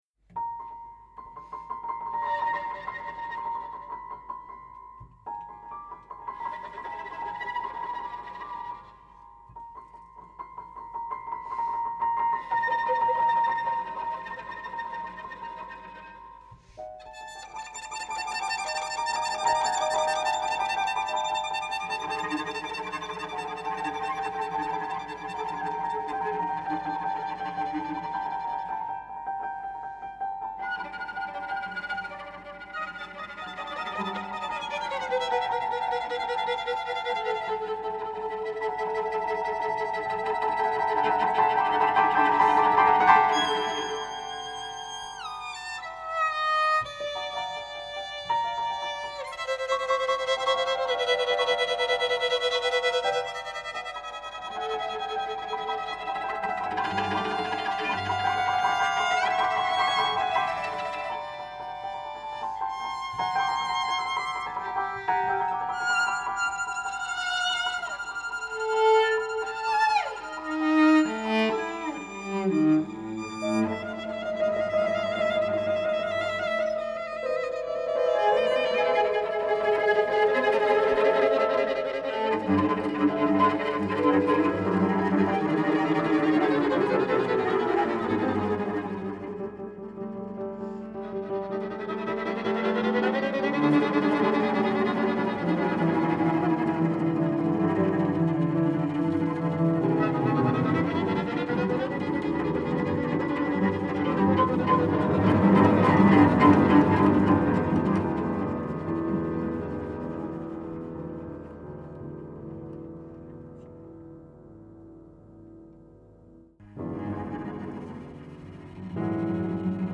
per violino, violoncello e pianoforte
Andante inquieto